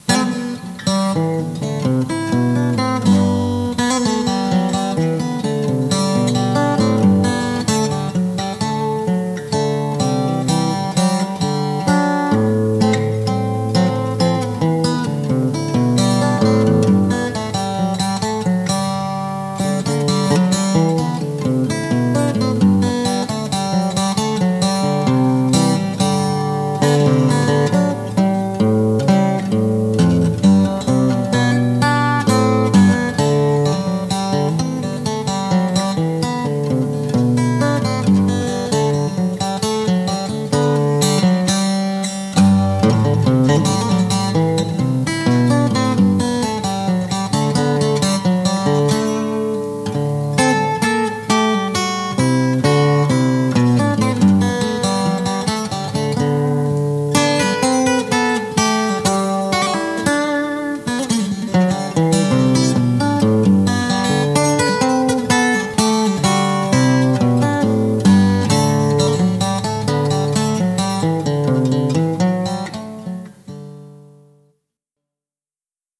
a short vaguely Renaissance-ish improvisation